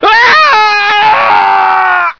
scream08.ogg